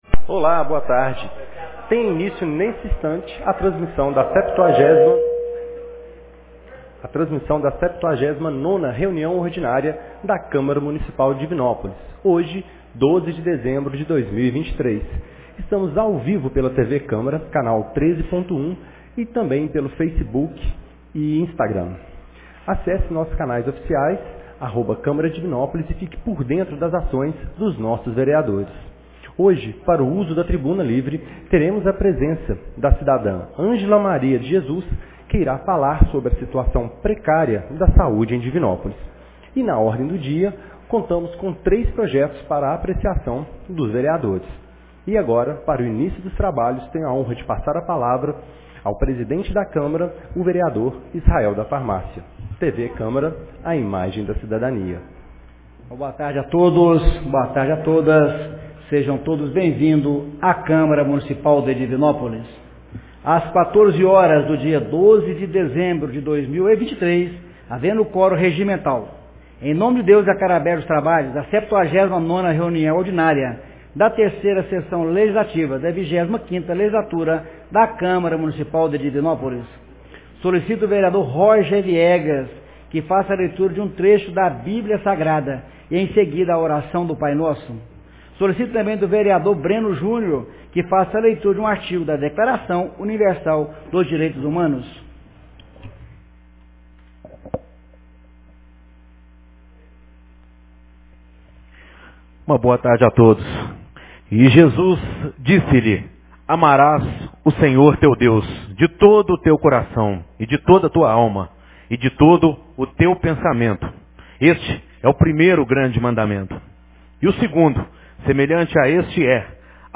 79ª Reunião Ordinária 12 de dezembro de 2023